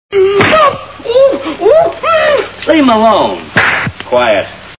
Three Stooges Movie Sound Bites
Sfx: Whap!